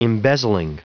Prononciation du mot embezzling en anglais (fichier audio)
embezzling.wav